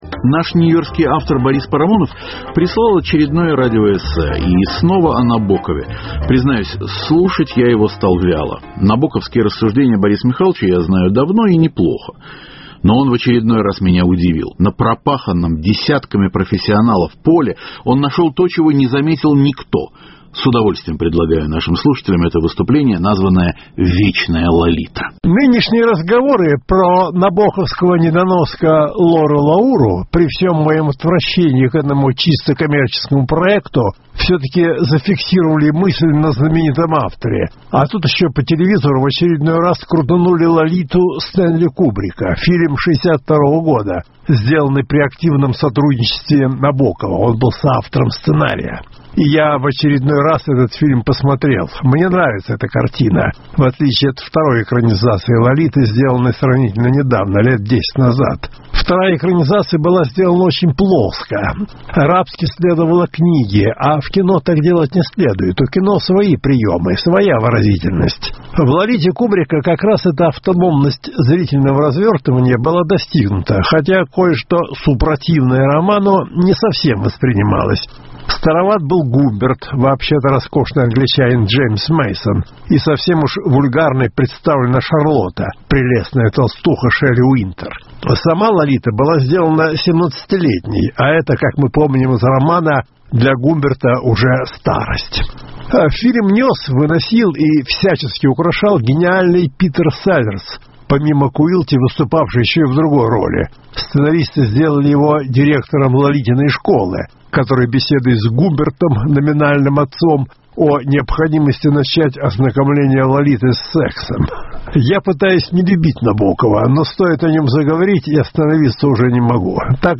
Вечная Лолита – радиоэссе Бориса Парамонова.